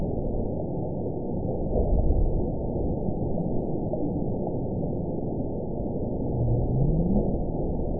event 917793 date 04/16/23 time 23:35:28 GMT (2 years ago) score 9.57 location TSS-AB05 detected by nrw target species NRW annotations +NRW Spectrogram: Frequency (kHz) vs. Time (s) audio not available .wav